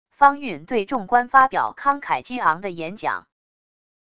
方运对众官发表慷慨激昂的演讲wav音频